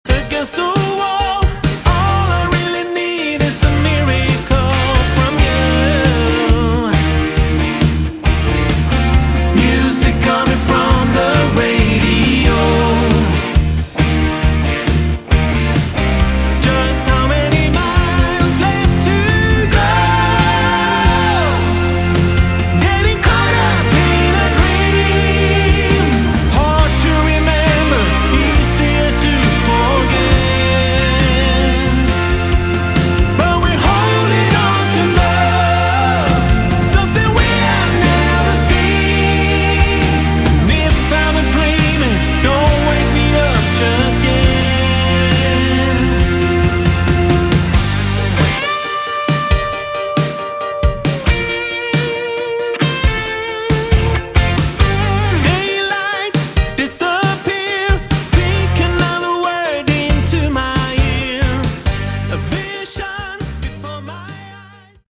Category: AOR / Melodic Rock
lead vocals, guitars
keyboards
bass
drums